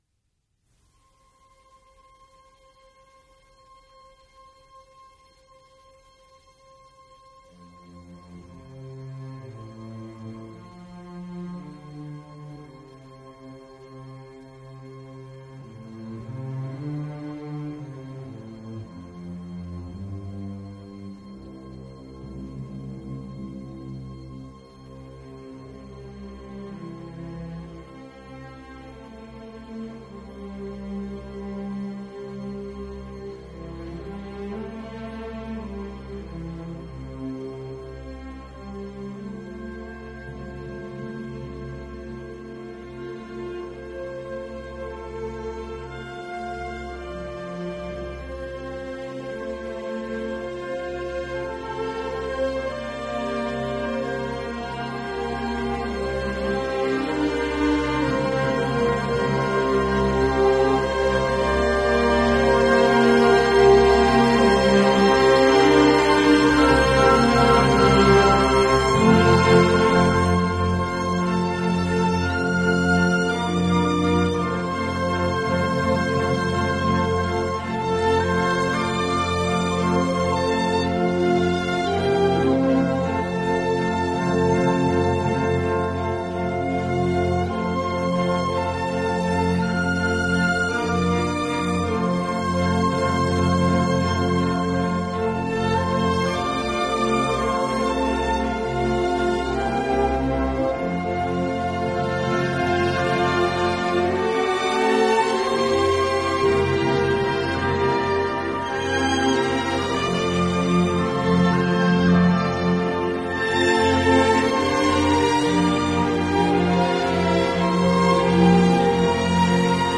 Programme 23: Light Classics with Spirit and Joy— a sparking set of light classical gems totalling approximately 55 minutes.
Nicolai, Otto The Merry Wives of Windsor – Overture 8:00 A spirited and tuneful curtain-raiser blending humour, elegance, and German charm.
Rossini, Gioachino La scala di seta – Overture 5:45 A bubbling, quicksilver overture full of playful wit and rhythmic sparkle.
Bizet, Georges L’Arlésienne Suite No. 2 – Farandole 3:30 A lively Provençal dance brimming with rhythmic energy and colourful orchestration.
Gounod, Charles Petite Symphonie for Winds – I. Adagio et Allegretto 8:00 Graceful and witty, a showcase of the warm blend of woodwind timbres.